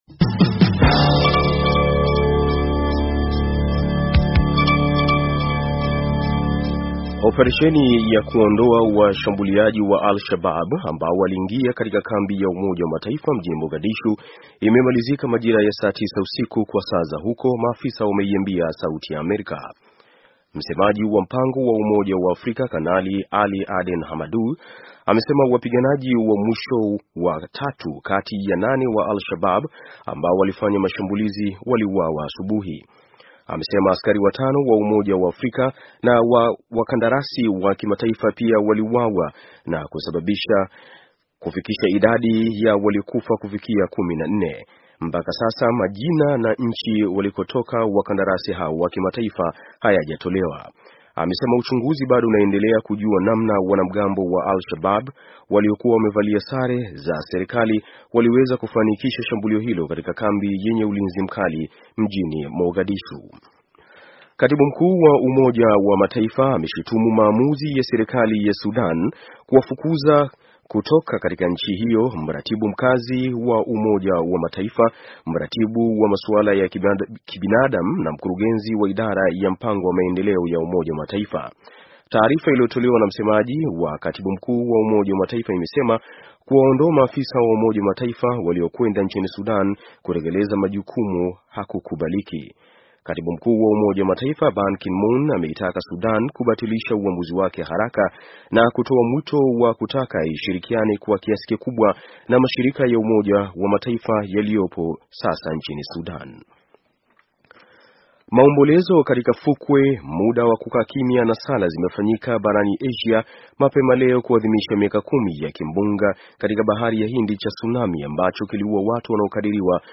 Taarifa ya habari - 6:11